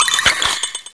pokeemerald / sound / direct_sound_samples / cries / vanillish.aif
vanillish.aif